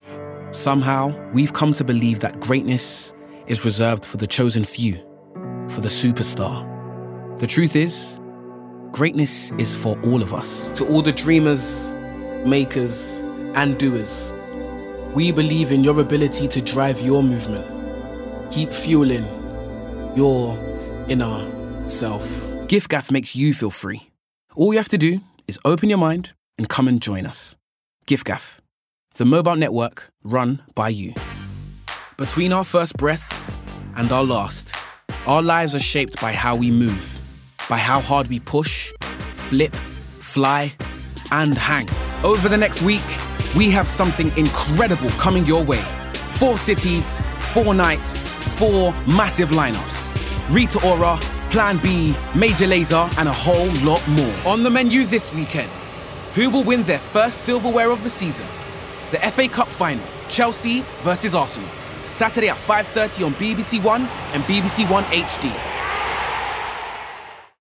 Commercial Showreel
London
Showreel, Bright, Natural, Smooth